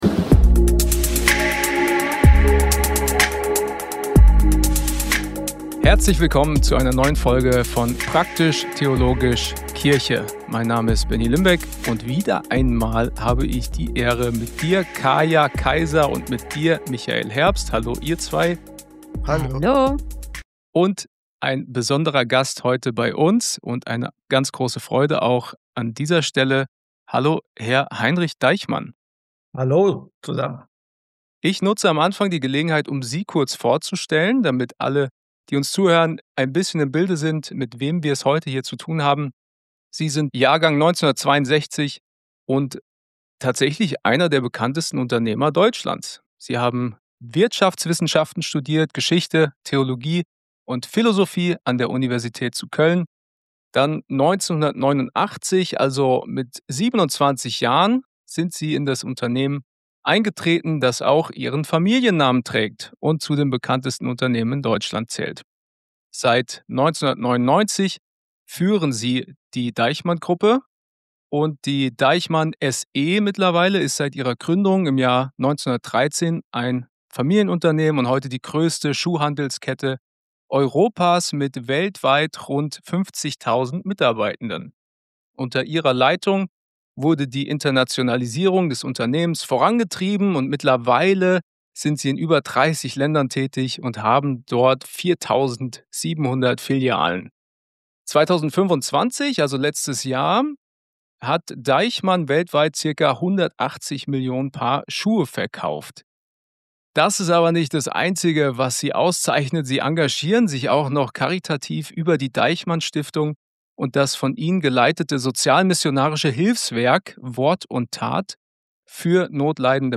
Wir befinden uns in unserer zweiten Staffel, in der wir mit faszinierenden Gästen ins Gespräch kommen! Wir reden mit ihnen über das, was sie begeistert und wo sie Chancen und neue Perspektiven für die Kirche sehen.
Heute zu Gast: Heinrich Deichmann.